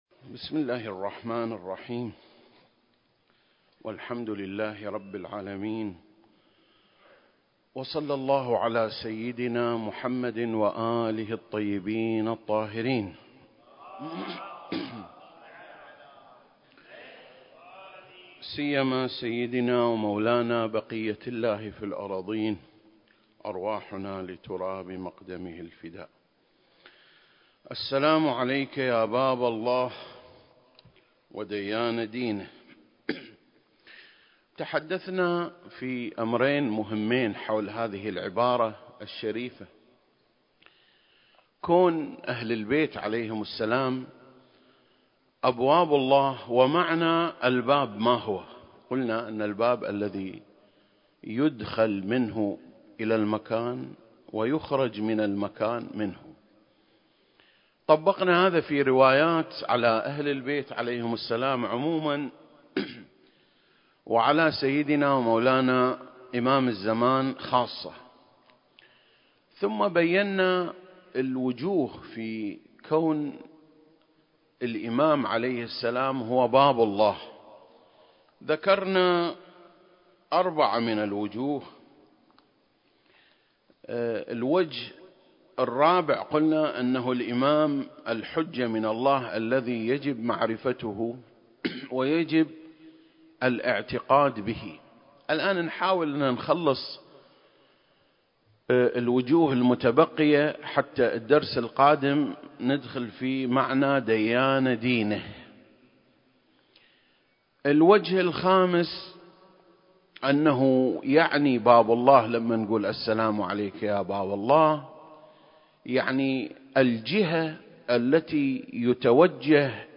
سلسلة: شرح زيارة آل ياسين (36) - باب الله (4) المكان: مسجد مقامس - الكويت التاريخ: 2021